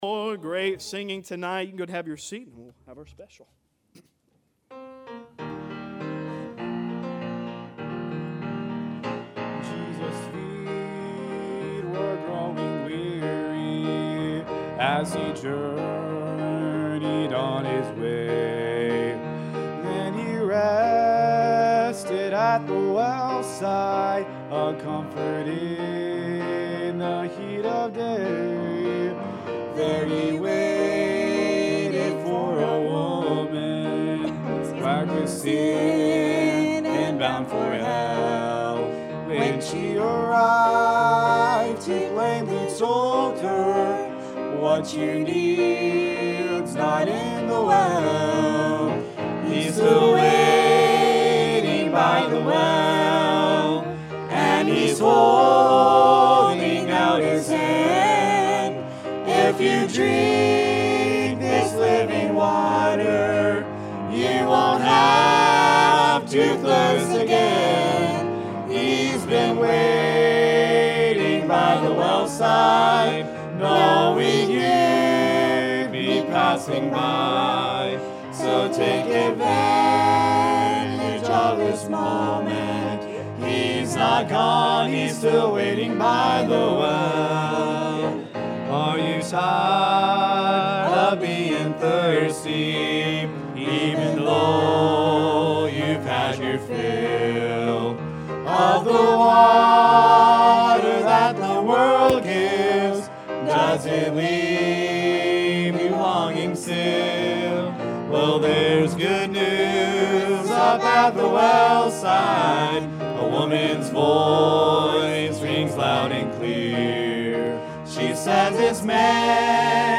Church at Philadelphia-Faithful Church | Sunday PM – Shasta Baptist Church